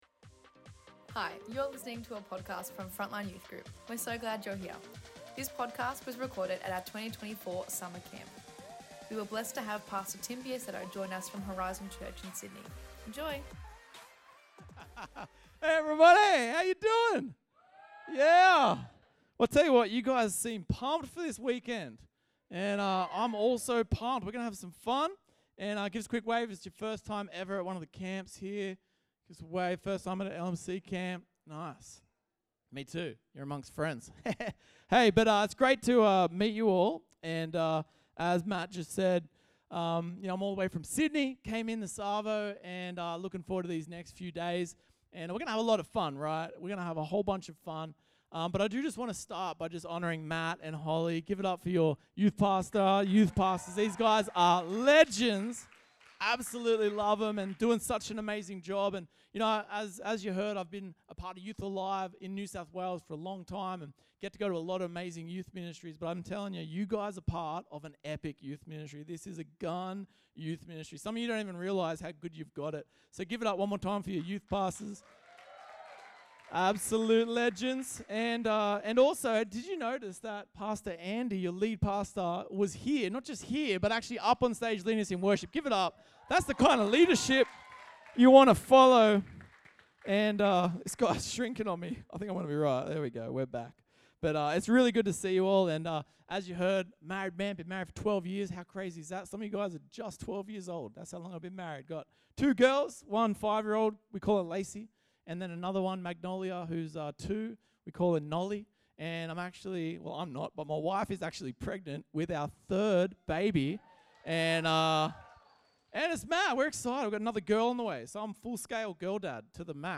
This was night one of our Youth Summer Camp 2024!